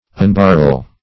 Search Result for " unbarrel" : The Collaborative International Dictionary of English v.0.48: Unbarrel \Un*bar"rel\, v. t. [1st pref. un- + barrel.] To remove or release from a barrel or barrels.